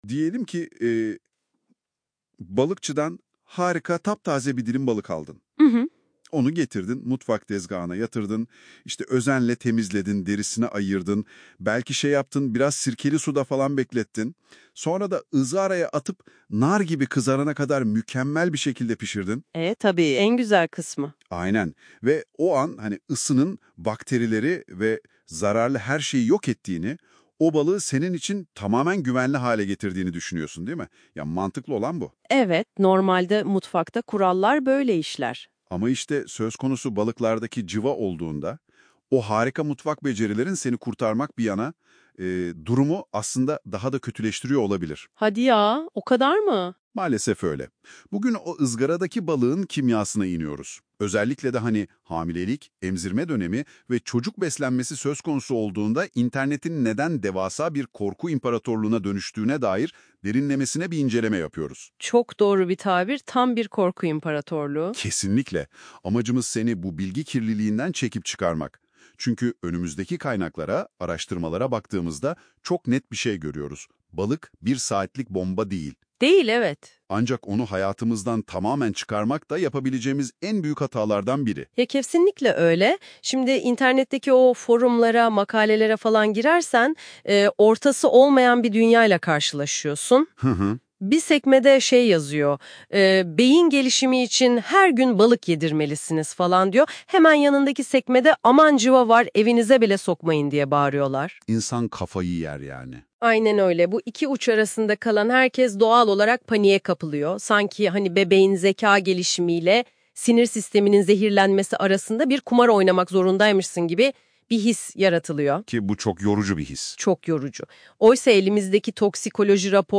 Bu yazı hakkında AI Podcast dinleyin by NotebookLM MP3 İndir Yasal Uyarı: Bu yazı genel bilgilendirme amaçlıdır; bireysel sağlık durumunuzda hekiminiz/çocuğunuzun pediatristi ve yerel balık tüketim uyarıları önceliklidir.